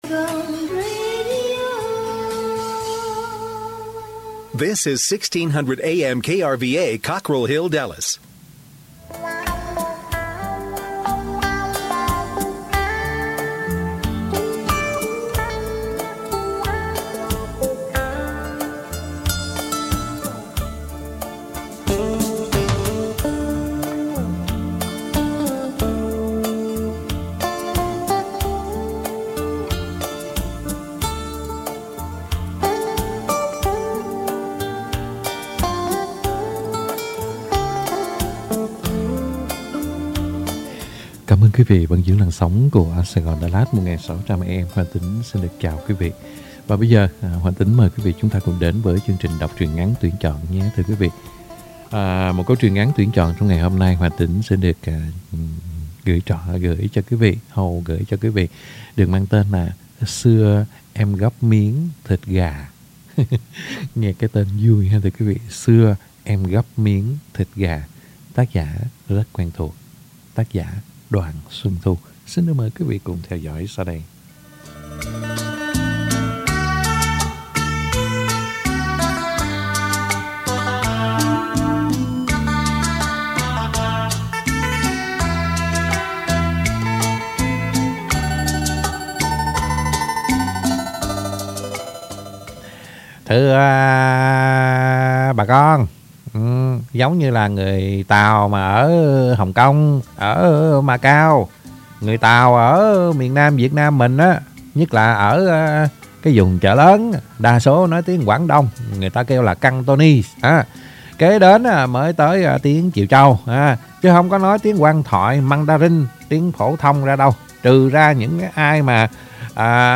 Đọc Truyện Ngắn = Xưa Em Gắp Miến Thịt Gà - 01/04/2022 .